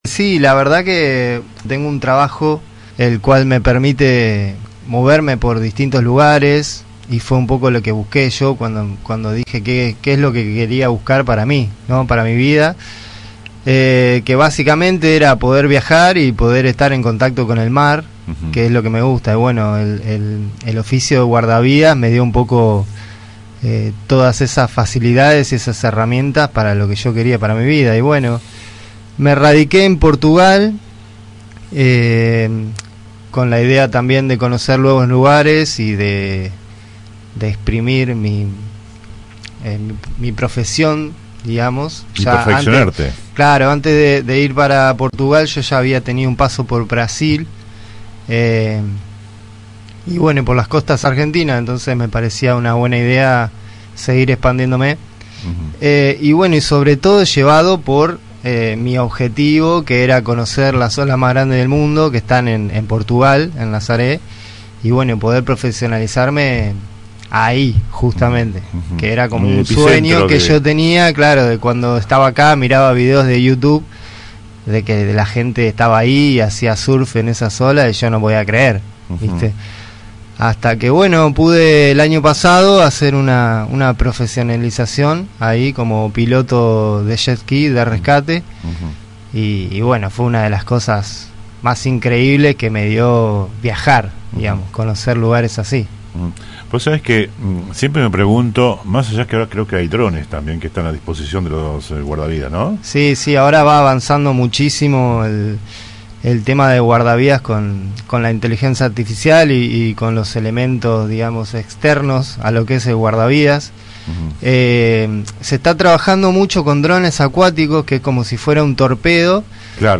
Entrevistamos al joven florense que se ha especializado en ser guardavida acuático, una profesión exigente en momentos donde los minutos cuentan.